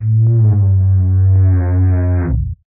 MOAN EL 12.wav